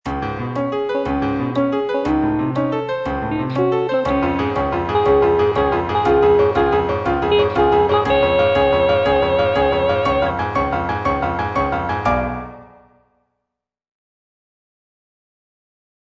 G. BIZET - CHOIRS FROM "CARMEN" En route, en route (soprano III) (Voice with metronome) Ads stop: auto-stop